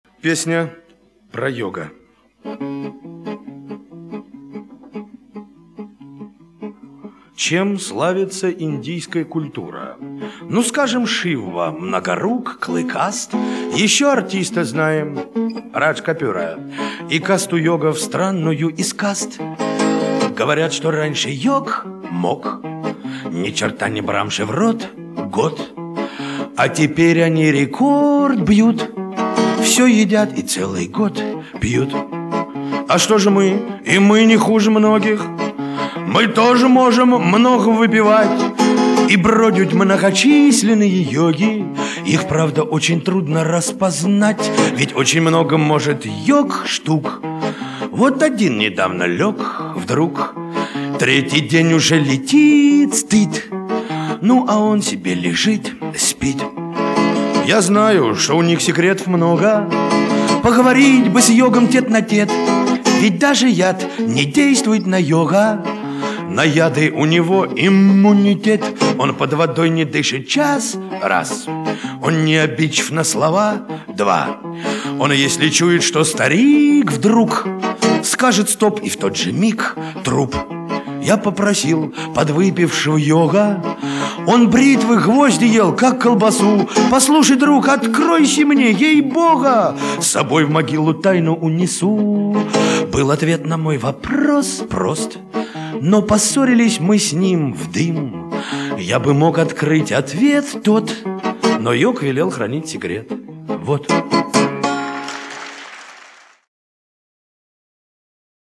Жанр: Bard